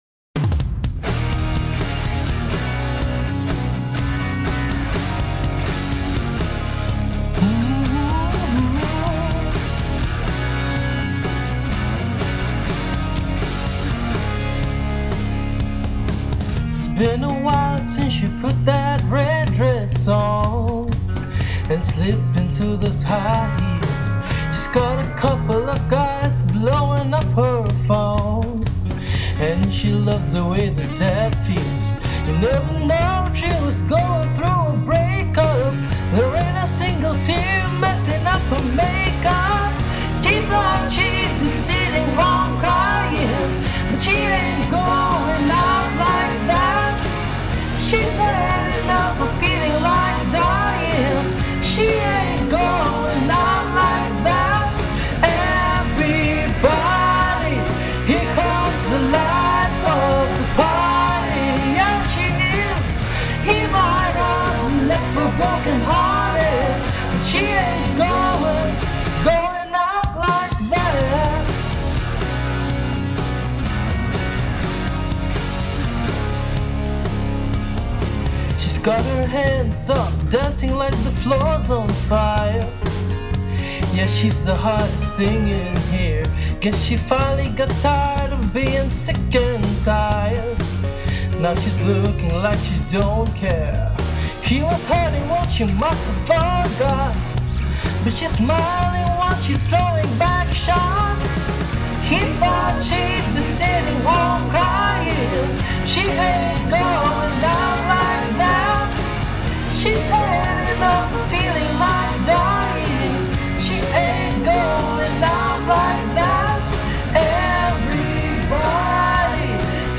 vocals span 1 octave & 4 notes, from E3 to B4.